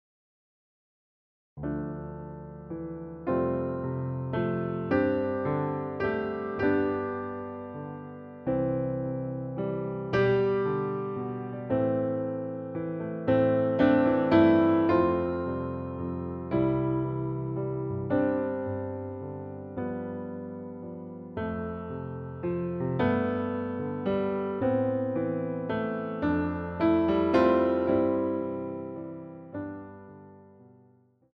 arranged for ballet class
WARMUP - 3/4